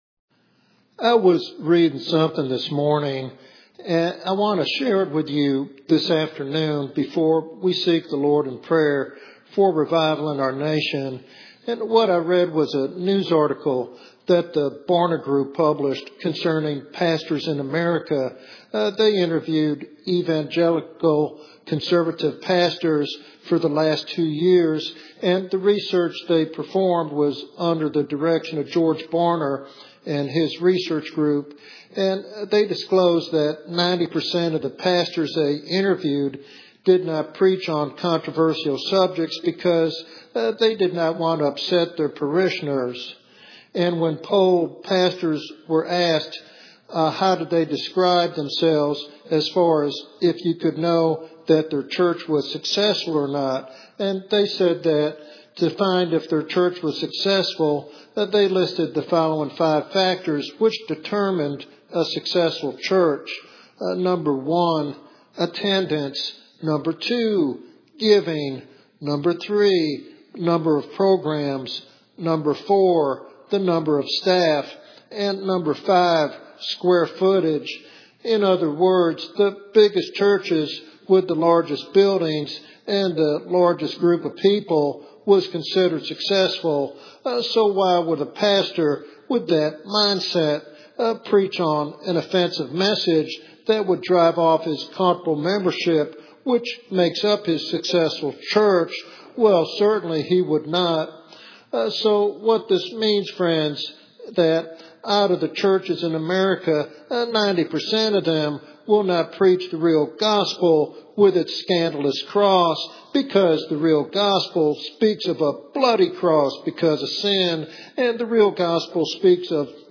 He calls for genuine repentance and warns of the eternal consequences of clinging to sin. This sermon is a powerful reminder of the necessity of true conversion and holiness in the Christian life.